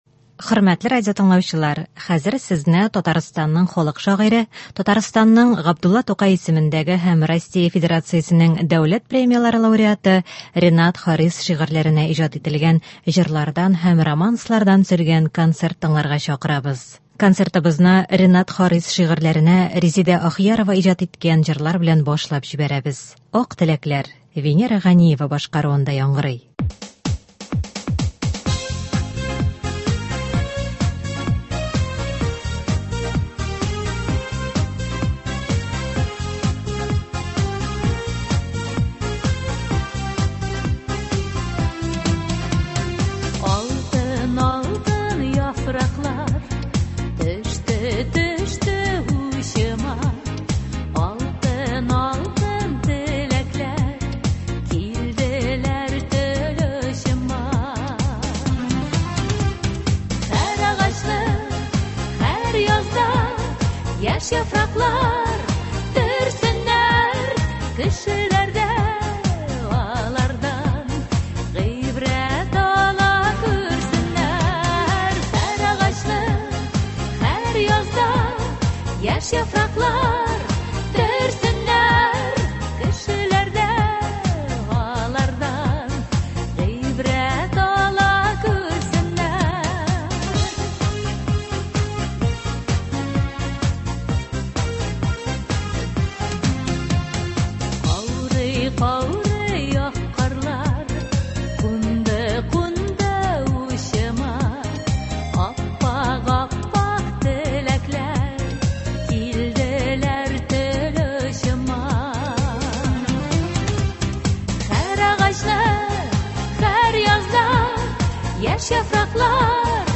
җырлар